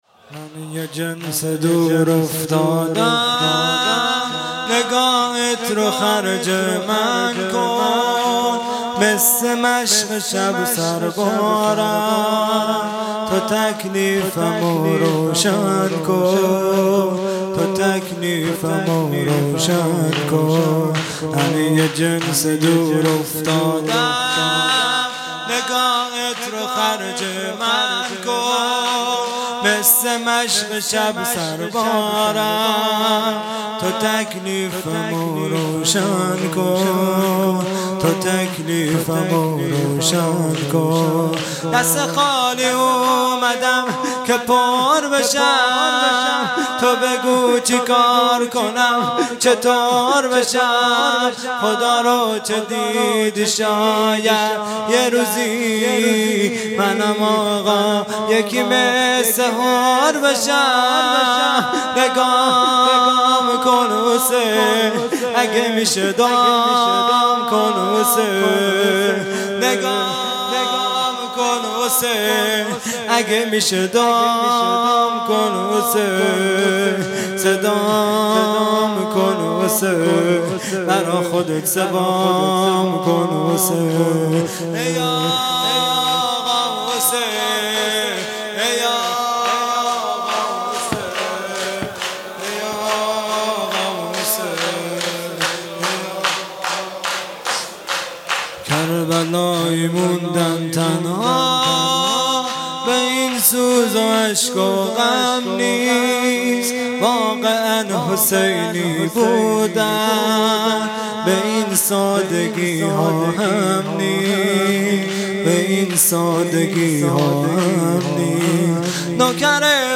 خیمه گاه - هیئت بچه های فاطمه (س) - شور | من یه جنس دور افتادم | 8 مرداد ۱۴۰۱